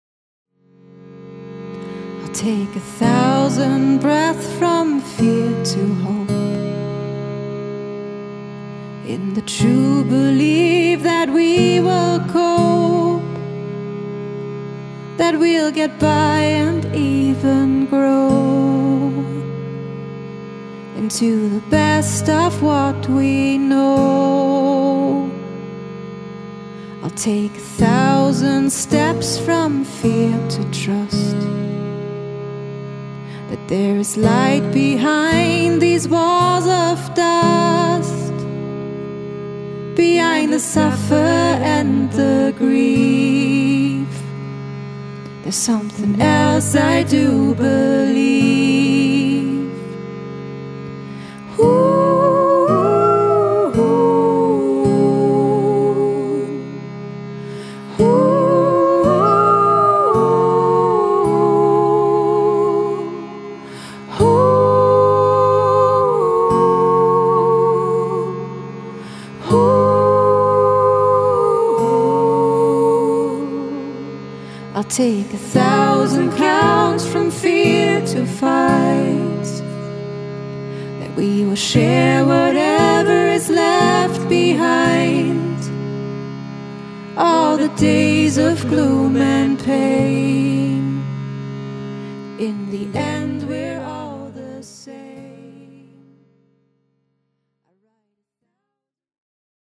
Live-Aufnahme